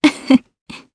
Ripine-Vox_Happy1_jp.wav